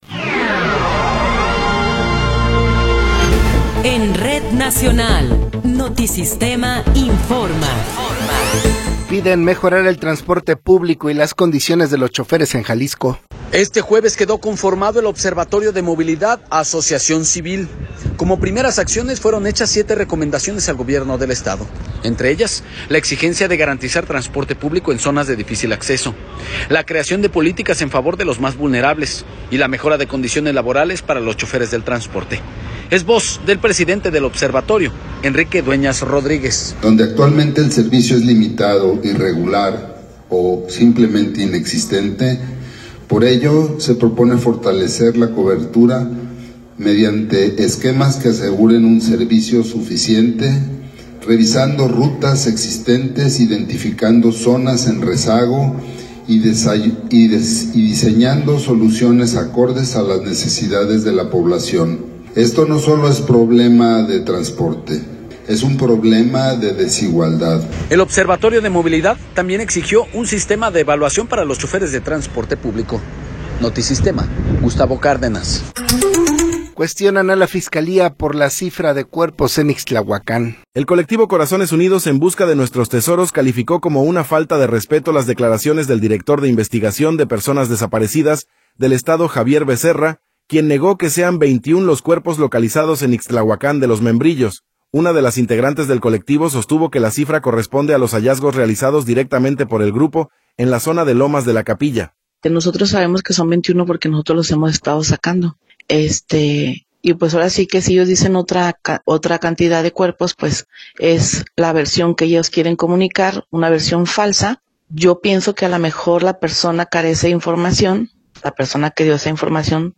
Noticiero 13 hrs. – 23 de Abril de 2026
Resumen informativo Notisistema, la mejor y más completa información cada hora en la hora.